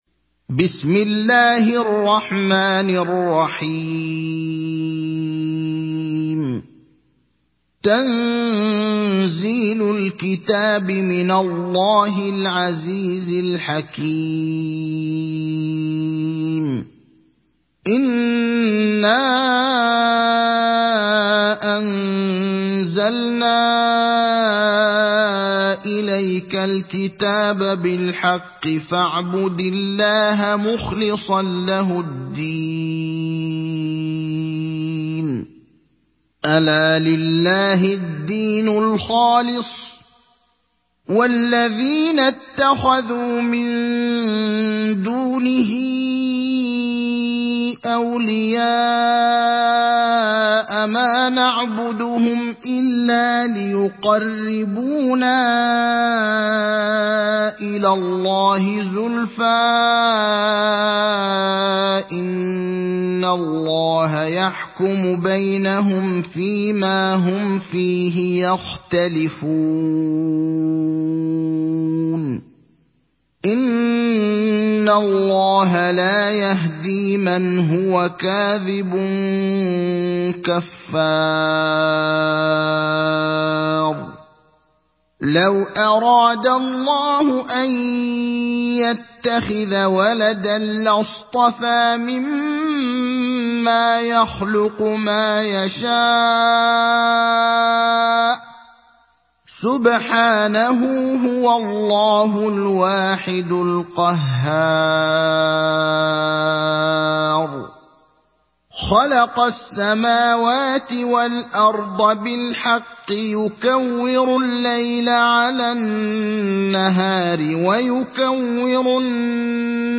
Surat Az zumar Download mp3 Ibrahim Al Akhdar Riwayat Hafs dari Asim, Download Quran dan mendengarkan mp3 tautan langsung penuh